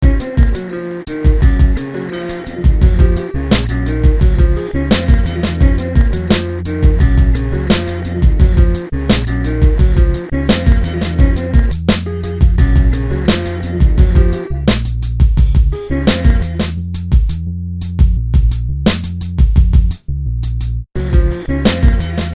Download the Hip Hop Beats